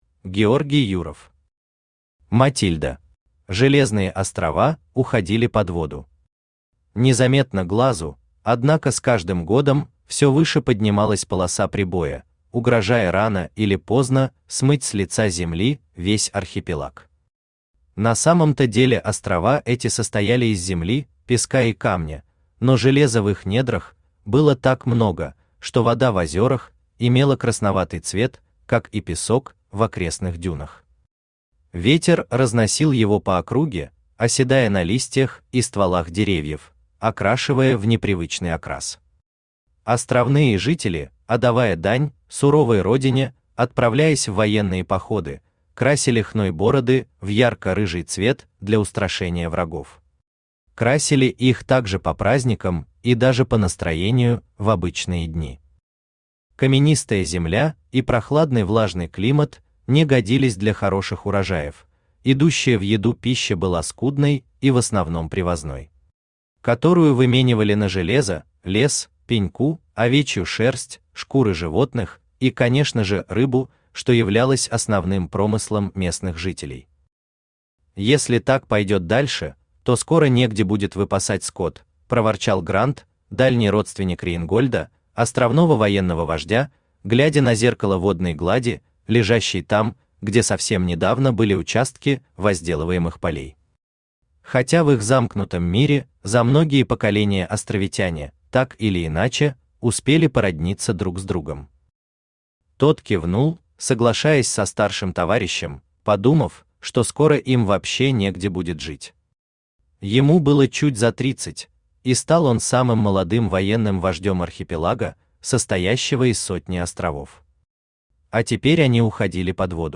Aудиокнига Матильда Автор Георгий Юров Читает аудиокнигу Авточтец ЛитРес. Прослушать и бесплатно скачать фрагмент аудиокниги